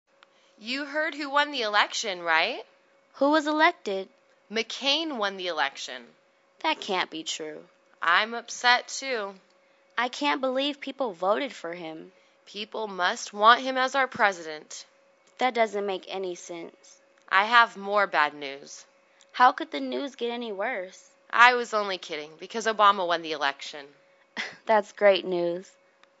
英语情景对话：Disappointed at the Result(2) 听力文件下载—在线英语听力室